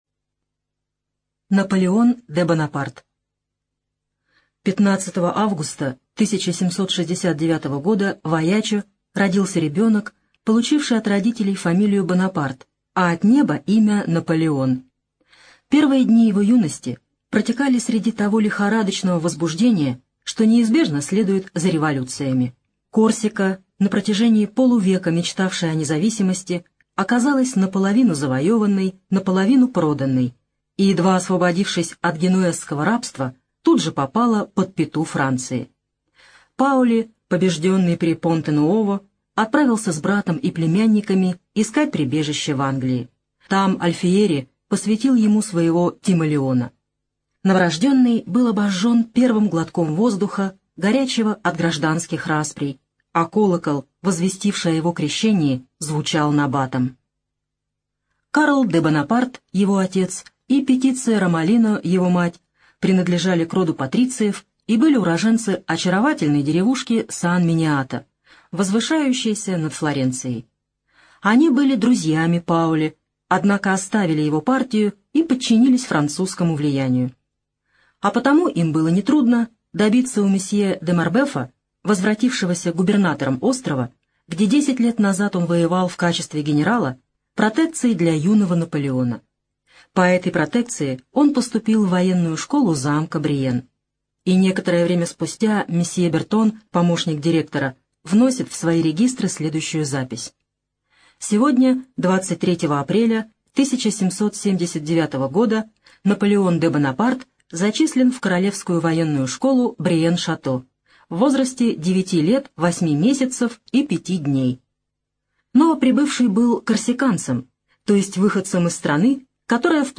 ЖанрИсторическая проза
Студия звукозаписиКемеровская областная специальная библиотека для незрячих и слабовидящих